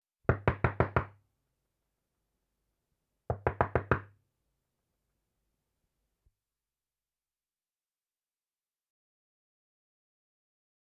זקוקה לאפקט של דפיקה בדלת
נקישות עקשניות.WAV